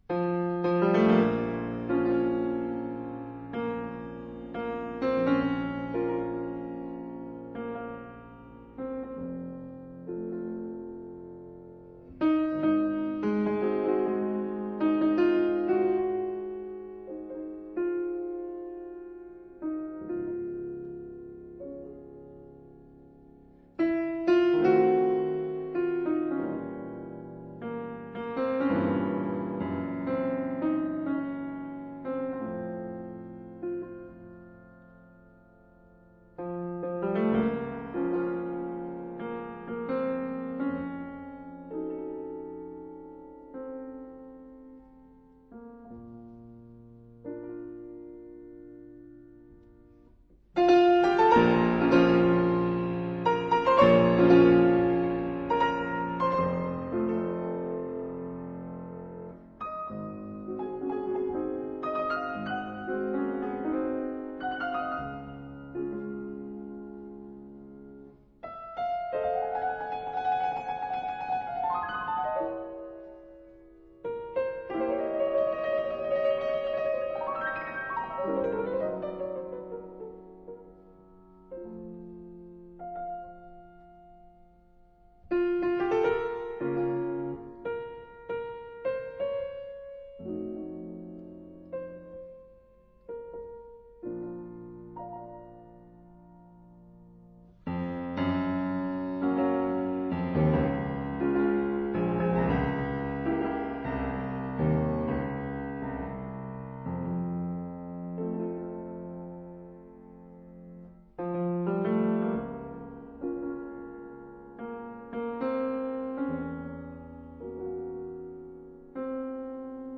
情深濃郁無比，色澤細膩到不斷地開大聲響來檢驗。
情感很濃。